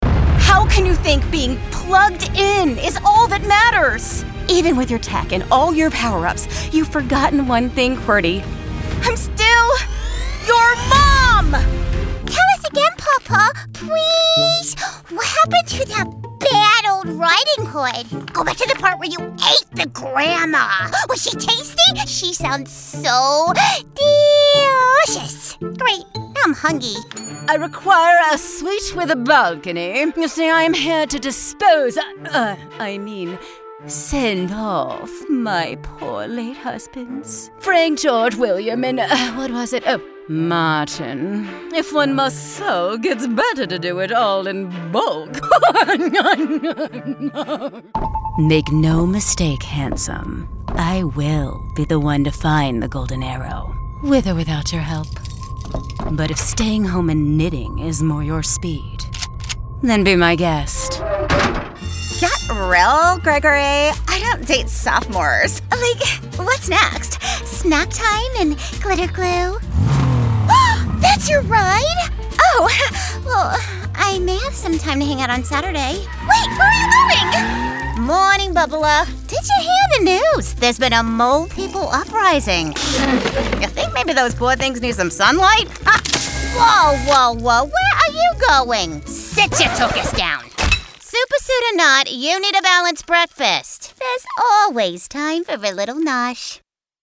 Animation Showreel
Female
American Standard
Bright
Friendly
Warm